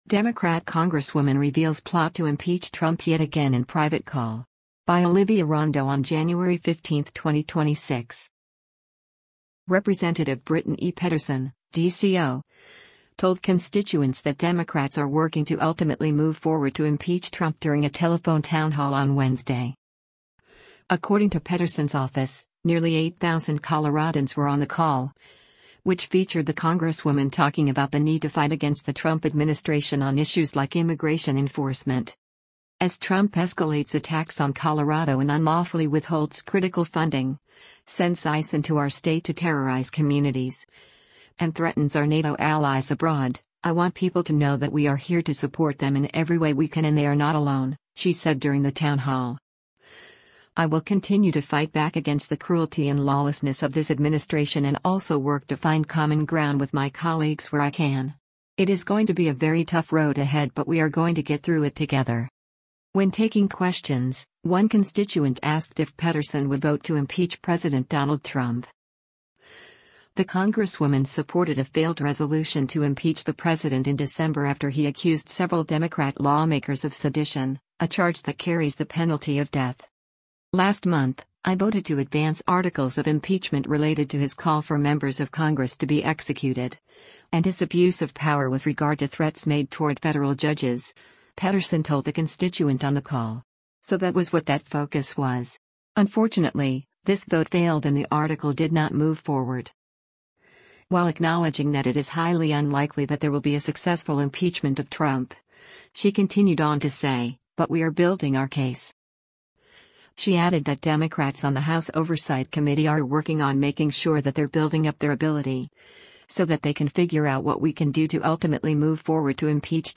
Rep. Brittany Pettersen (D-CO) told constituents that Democrats are working to “ultimately move forward to impeach Trump” during a telephone town hall on Wednesday.
When taking questions, one constituent asked if Pettersen would vote to impeach President Donald Trump: